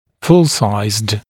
[ful-saɪzd][фул-сайзд ]полноразмерный